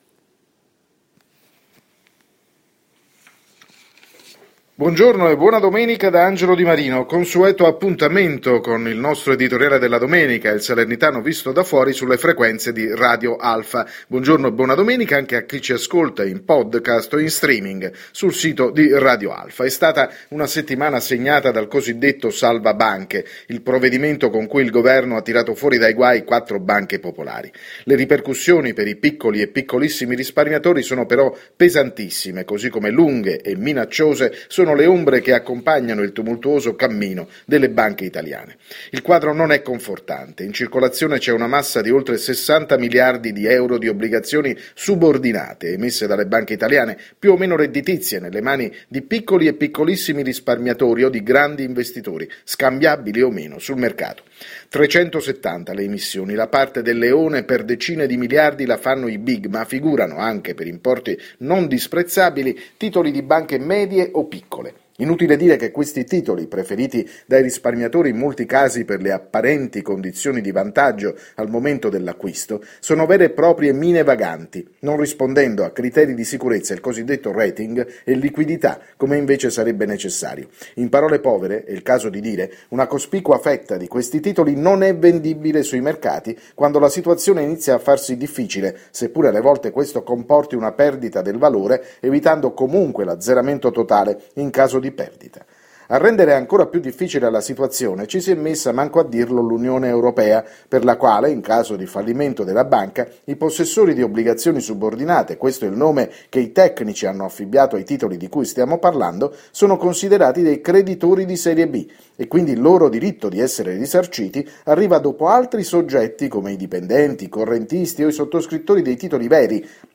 L’editoriale della domenica andato in onda sulle frequenze di Radio Alfa questa mattina.